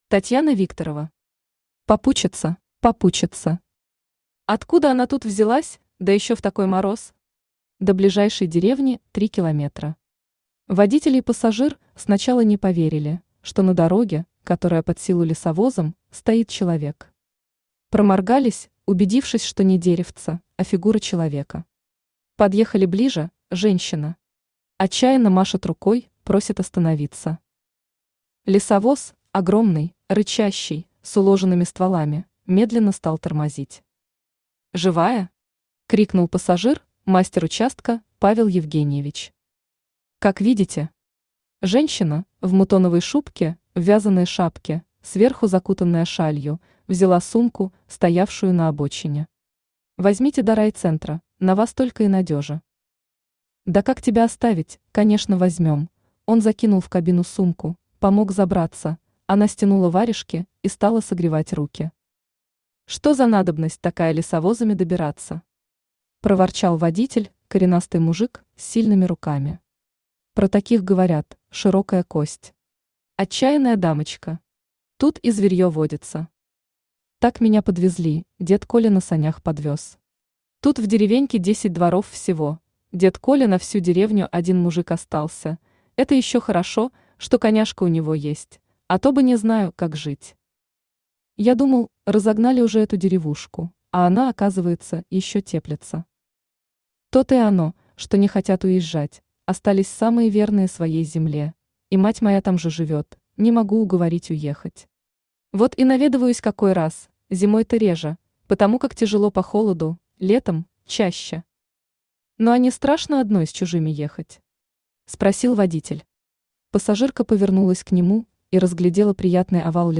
Aудиокнига Попутчица Автор Татьяна Викторова Читает аудиокнигу Авточтец ЛитРес. Прослушать и бесплатно скачать фрагмент аудиокниги